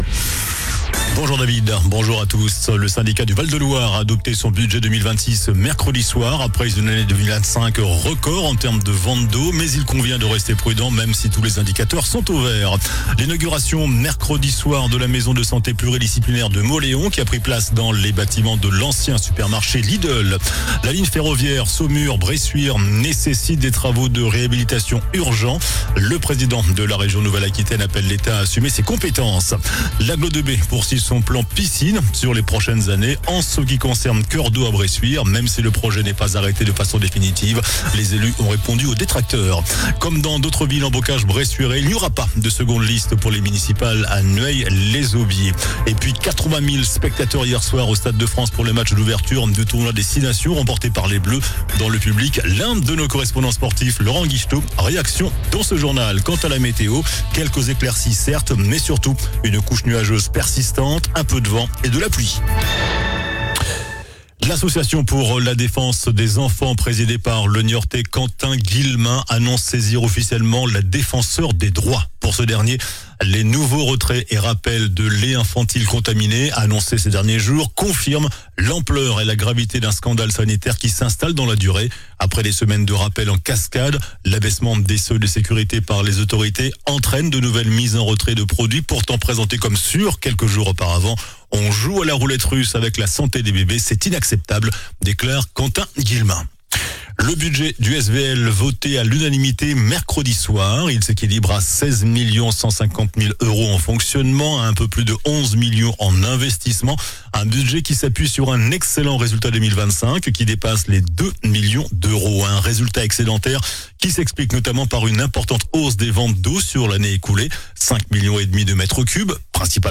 JOURNAL DU VENDREDI 06 FEVRIER ( MIDI )